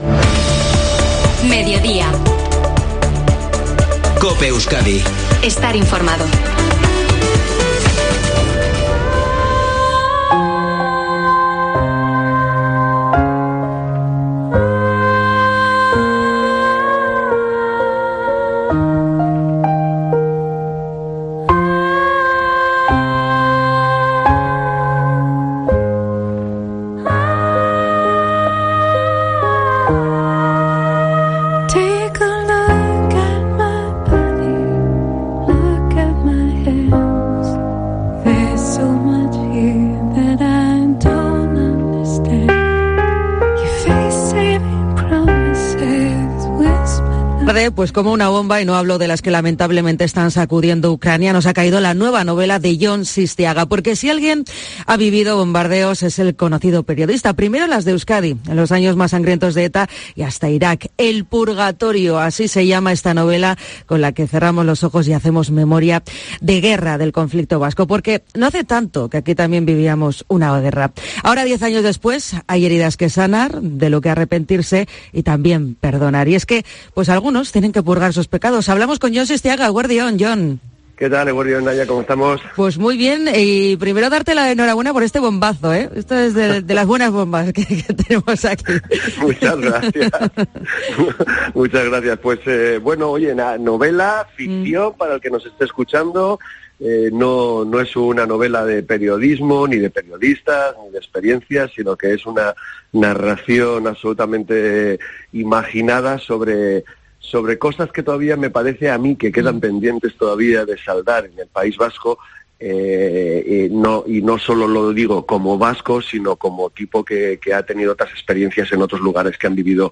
Jon Sistiaga, en COPE Euskadi